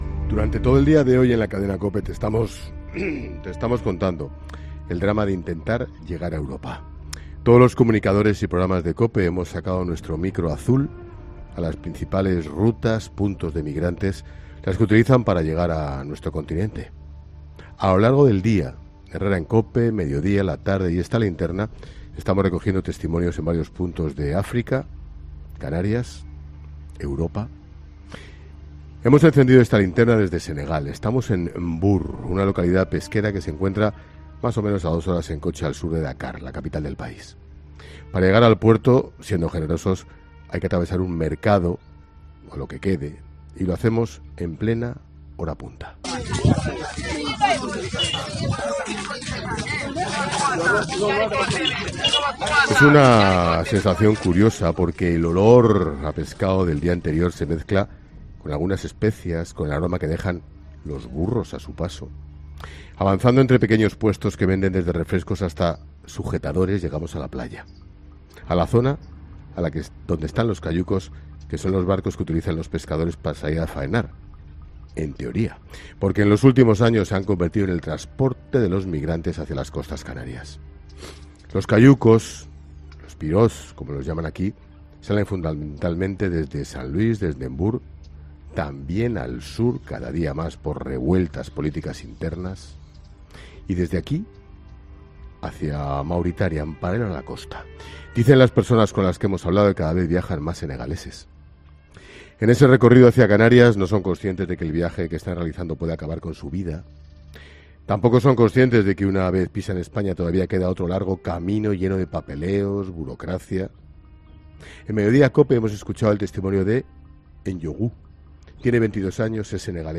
Ángel Expósito enciende La Linterna desde Senegal